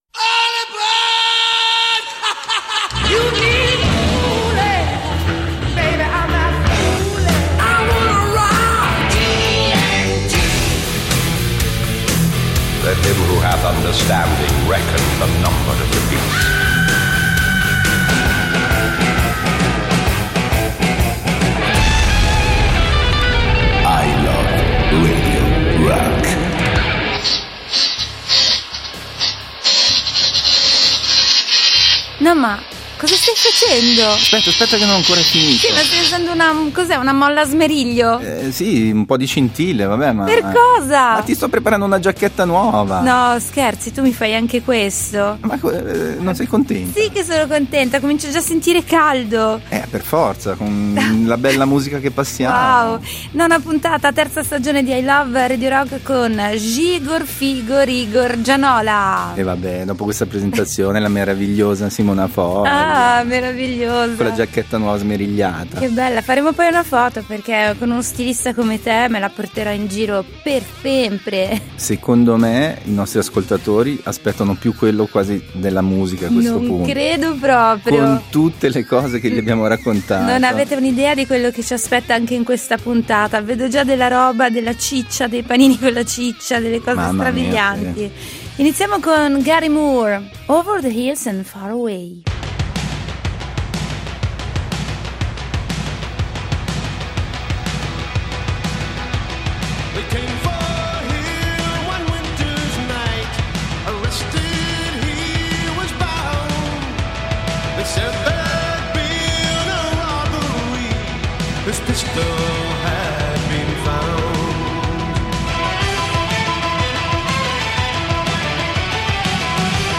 Il miglior programma di rock duro della Svizzera italiana.